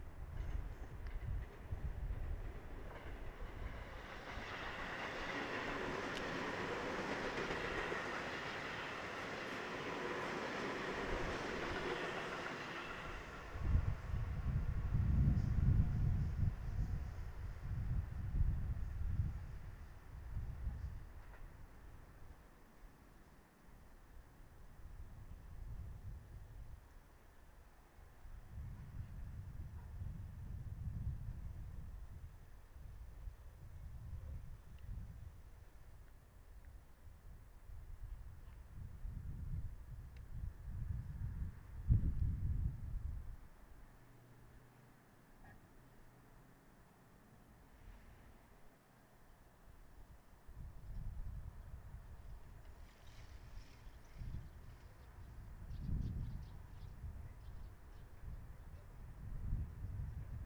「清瀬立体の上の西武線の走行音」　2020年5月24日
F-99Aは、内蔵のウインドスクリーンだけでは風による吹かれ音があります。
F-99Aは、PCM-D10で録音
録音フォーマット／リニアPCM 96kHz24bit